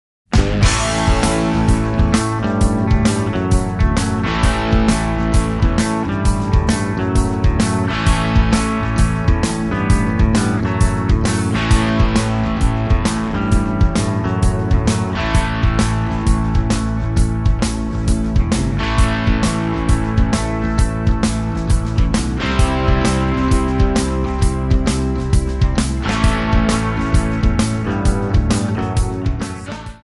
Em
MPEG 1 Layer 3 (Stereo)
Backing track Karaoke
Pop, Rock, 1980s